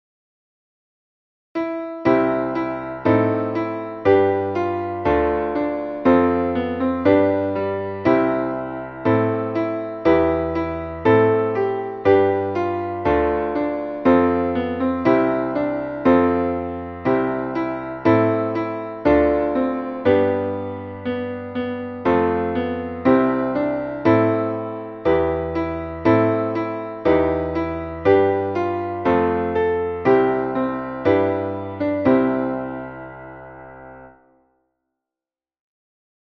Traditional Christmas carol